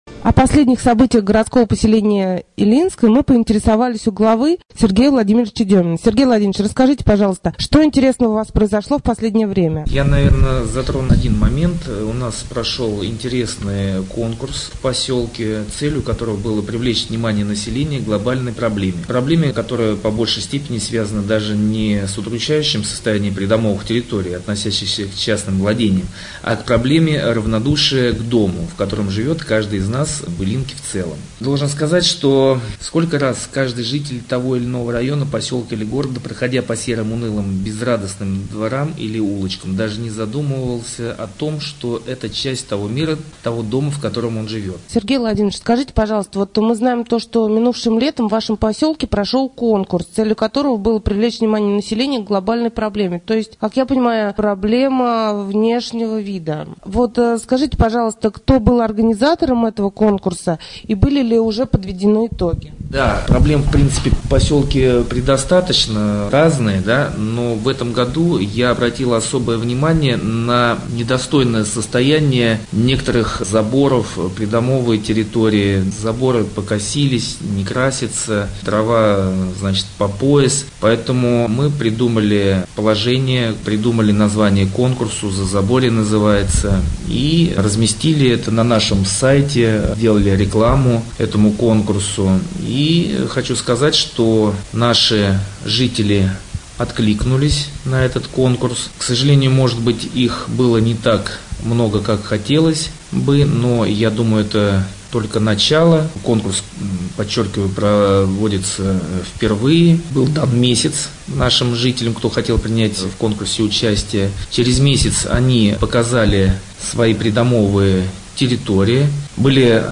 24.10.2013г. в эфире раменского радио - РамМедиа - Раменский муниципальный округ - Раменское